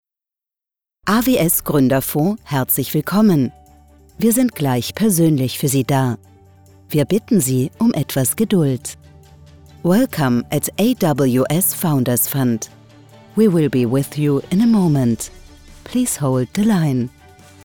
Professional german and austrian phone messages: mp3, wave, aiff, A-Law
sample initial greeting script german